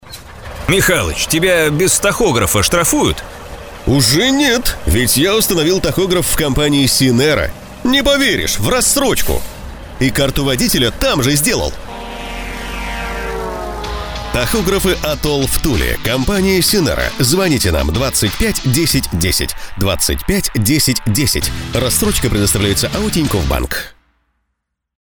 Началось размещение рекламы на радиостанции "Юмор FM" компании "SYNERA" в г. Туле.